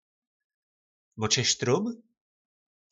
E | Slovník nářečí Po našimu